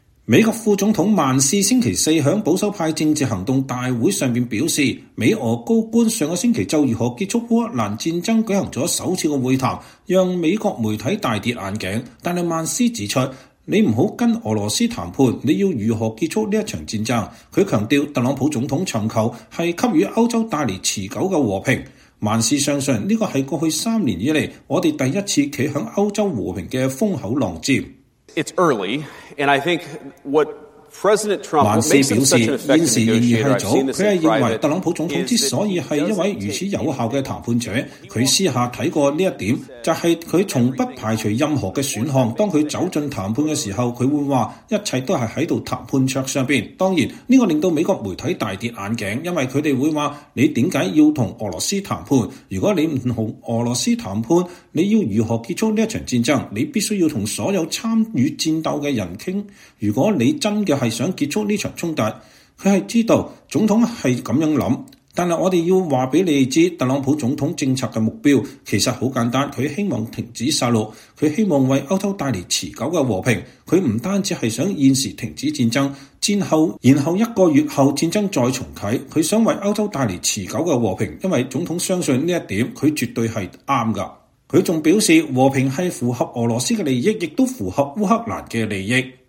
美國副總統萬斯(JD Vance)週四在保守派政治行動大會(CPAC)上表示，美俄高官上週就如何結束烏克蘭戰爭舉行首次會談讓美國媒體大跌眼鏡，但萬斯指出，“你不跟俄羅斯談判，你要如何結束這場戰爭？”他強調，特朗普總統尋求的是給歐洲帶來持久的和平。